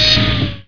sawblade_off.wav